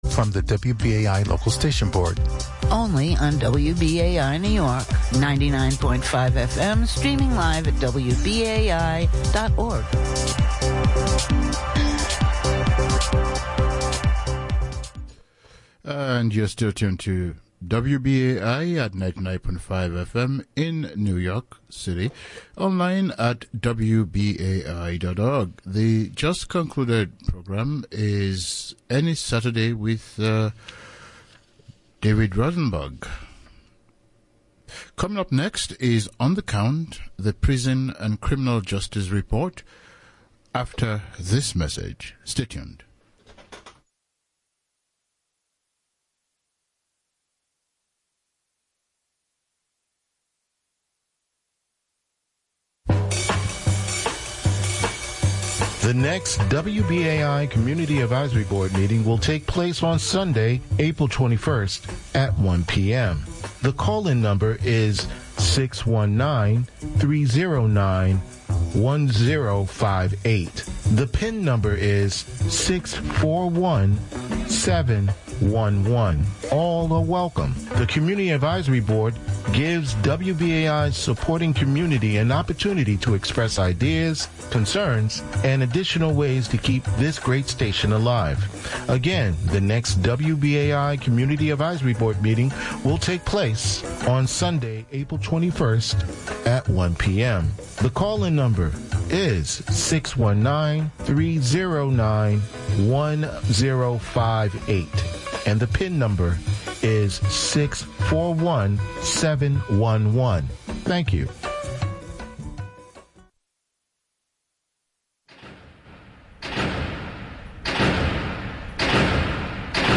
Fortune Poets Featured on WBAI “On the Count”